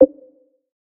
click-short-confirm.ogg